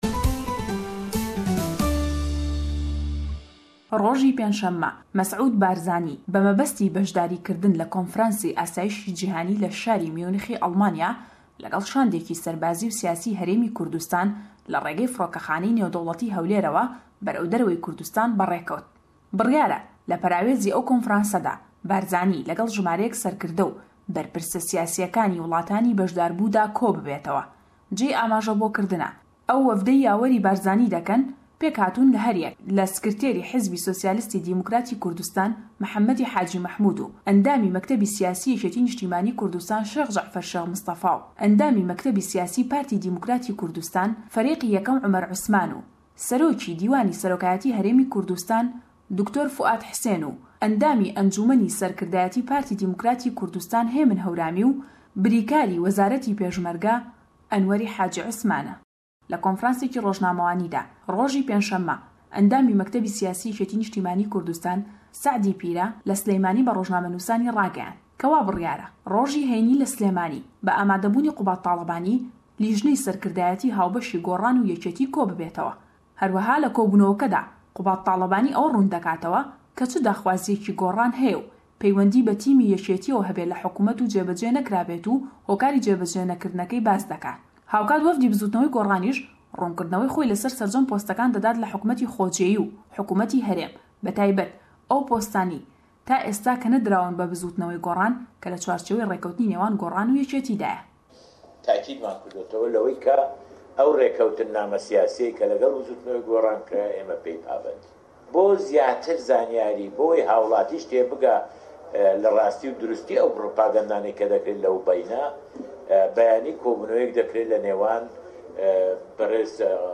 eme û çendîn hewallî dîkey nawceke le em raportey peyamnêrman